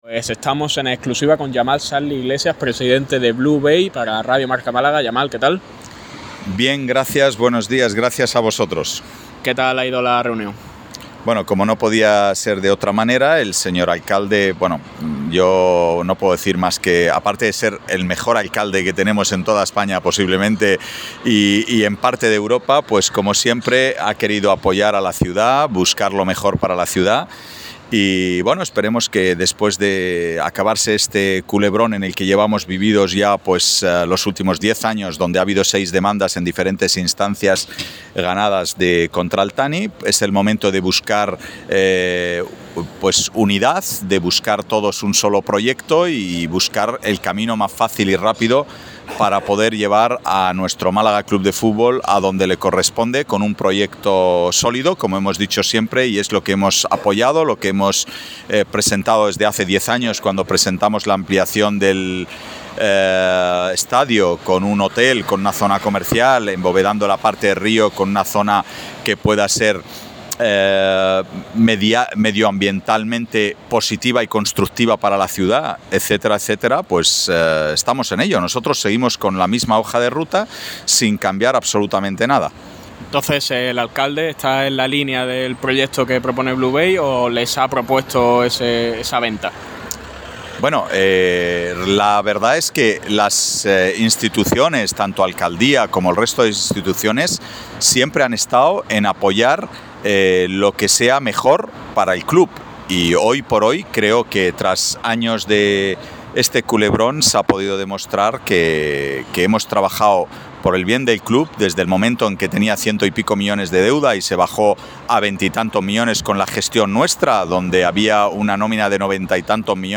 ha contado los detalles de la misma al micrófono rojo